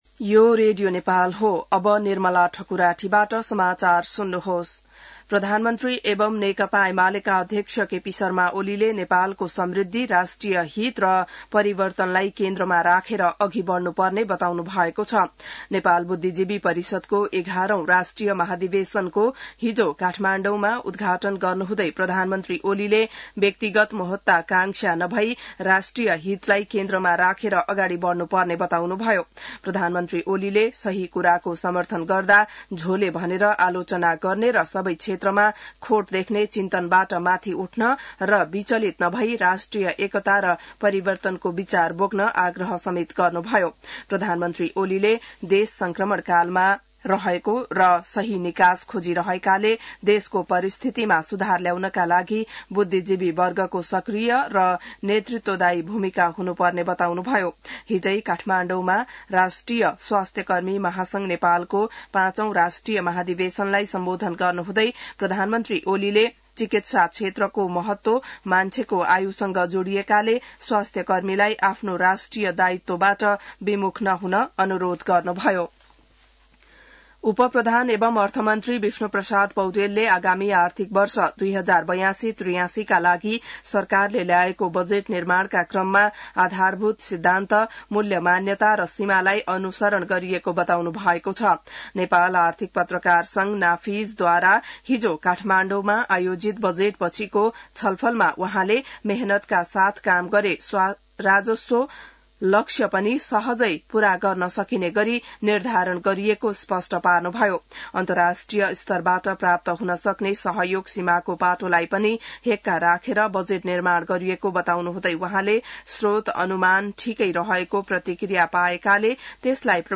बिहान ६ बजेको नेपाली समाचार : १८ जेठ , २०८२